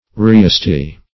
Reasty \Reas"ty\ (r[=e]s"t[y^]), a. [Etymol. uncertain.]